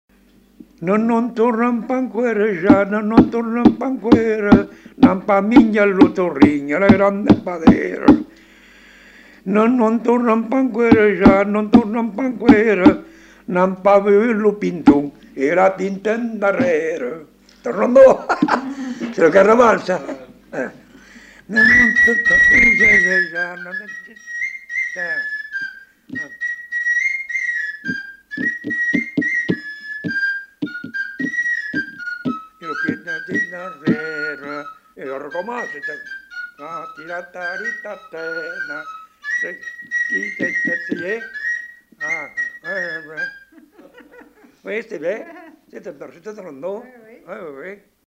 Aire culturelle : Bazadais
Genre : chanson-musique
Type de voix : voix d'homme
Production du son : chanté
Instrument de musique : flûte à trois trous
Danse : rondeau